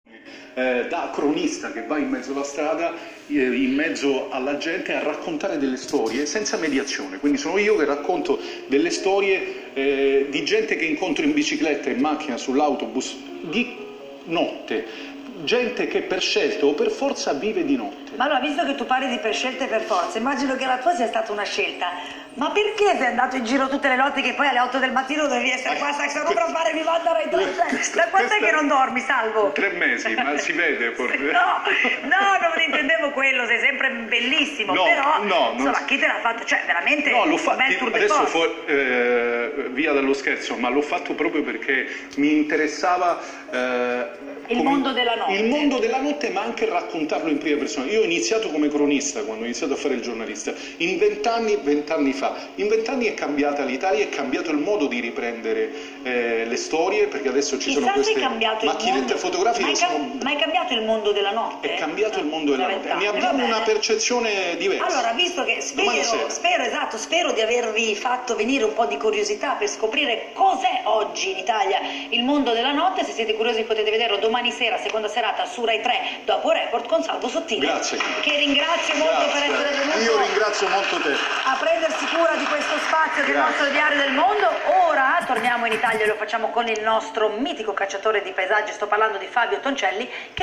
Nella clip audio che segue, è possible ascoltare la presentazione della trasmissione, che Sottile ha avuto modo di fare ieri pomeriggio durante il contenitore pomeridiano di Rai3 curato da Camilla Ratznovich.